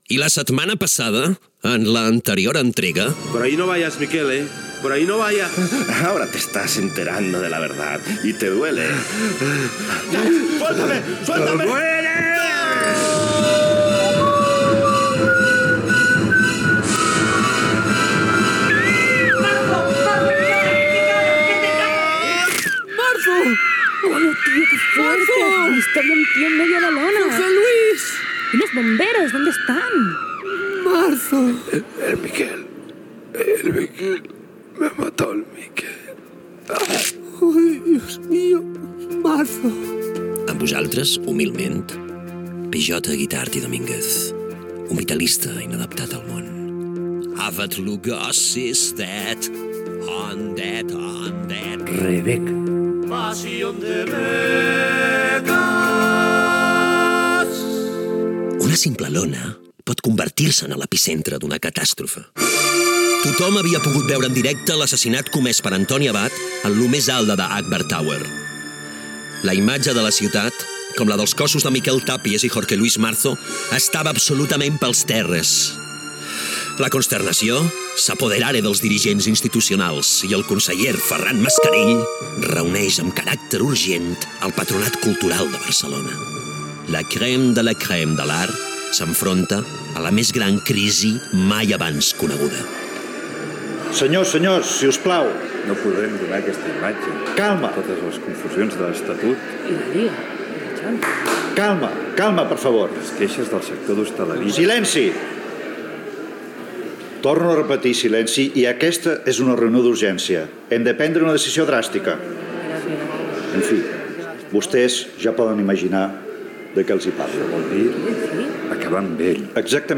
Cançó original del serial Gènere radiofònic Ficció